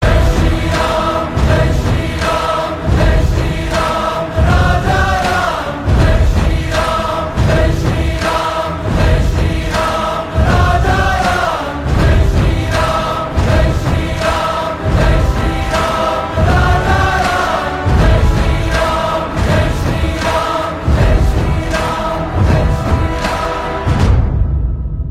Download devotional MP3 ringtone with loud, clear sound.
• Pure devotional (Bhakti) vibe